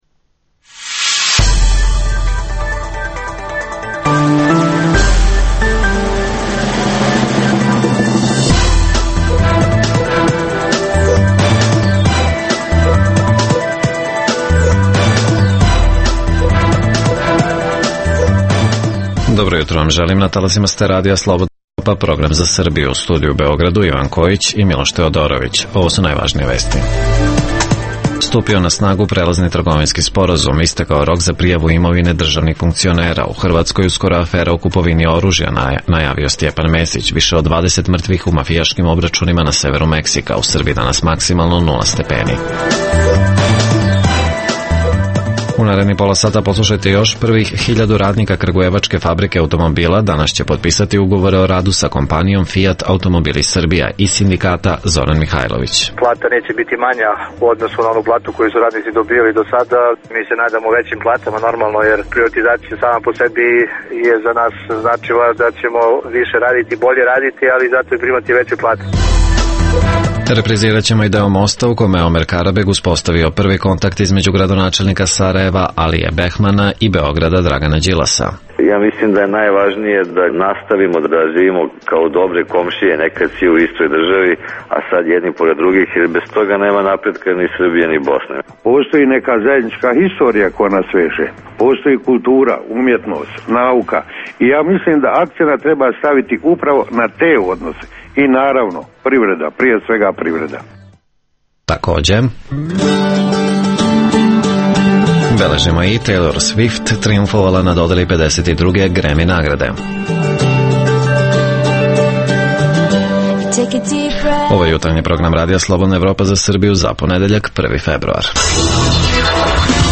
Godinu dana nakon što je Srbija otpočela sa jednostranom primenom i mesec dana nakon što ga je EU odmrzla, stupio je na snagu Prelazni trgovinski sporazum. O tome razgovaramo sa direktorkom Kancelarije Vlade Srbije za pridruživanje EU Milicom Delović. Takođe, u ponoć je istekao rok za državne funkcionere u Srbiji u kom su morali da Agenciji za borbu protiv korupcije prijave svoje imovinske karte.